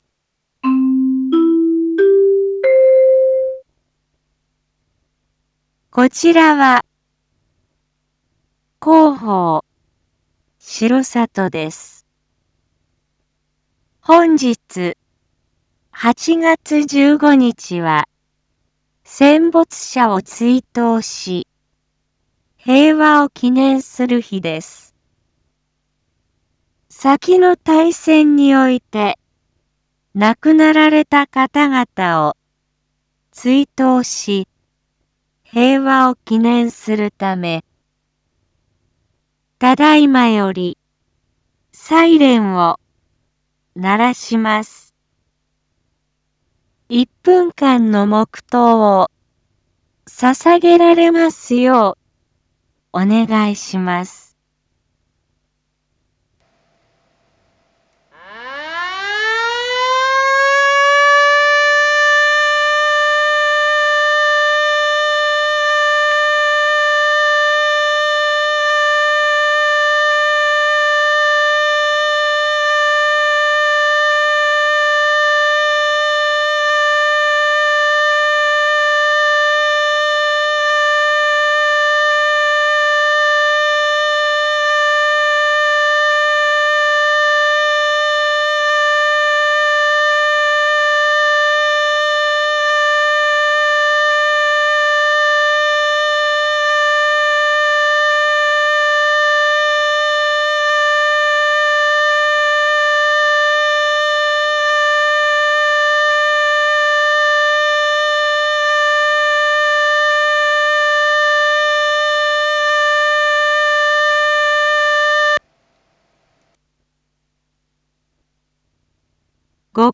一般放送情報
Back Home 一般放送情報 音声放送 再生 一般放送情報 登録日時：2023-08-15 12:01:14 タイトル：全国戦没者追悼について インフォメーション：こちらは、広報しろさとです。
先の大戦において亡くなられた方々を追悼し、平和を祈念するため、ただいまより、サイレンを鳴らします。